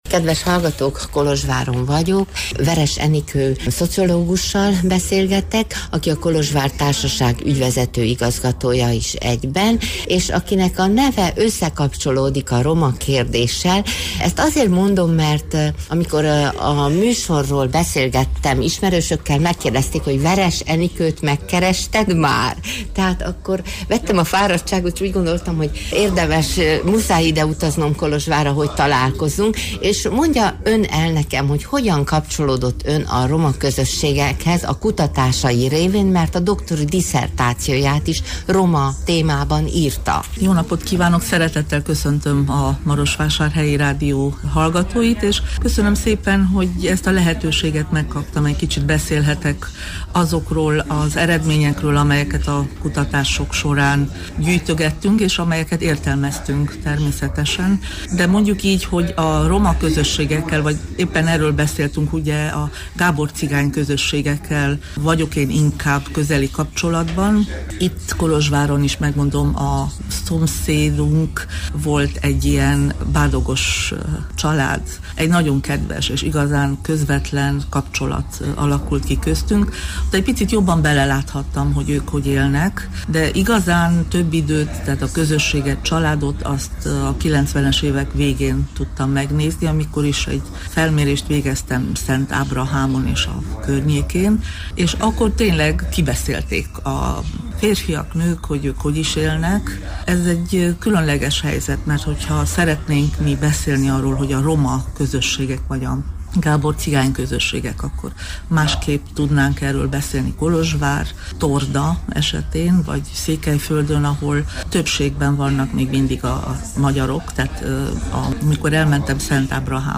Egy kávé mellett, egy (remélhetőleg) nem túl zajos kolozsvári kávézóban szívesen mesélt tapasztalatairól, roma származású ismerőseiről, azoknak életmódjáról, szokásairól. A beszélgetés során az is kiderült, hogy folytatni szeretné ezt a tapasztalatszerzést, ugyanis azt tervezi, hogy idén nyáron felkeresi régi ismerőseit, hogy lássa, miként élnek most, hogyan alakult az életük az évek során.